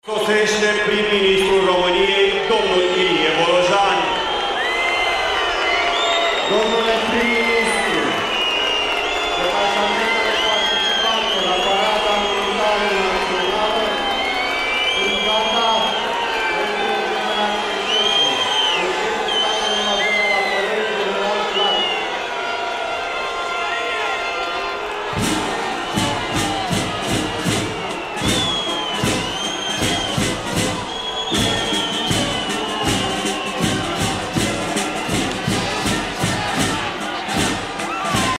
Premierul Ilie Bolojan, huiduit la parada de Ziua Națională de la Alba Iulia | AUDIO
Ajuns la ceremonia din Alba Iulia, premierul Ilie Bolojan a fost huiduit de o parte dintre oamenii care au venit să vadă parada militară de Ziua Națională.
În momentul în care prim-ministrul a coborât din mașină, câțiva oameni au început să fluiere, să huiduie și să strige „Demisia!”
01dec-15-ambianta-Bolojan-huiduit-la-Alba.mp3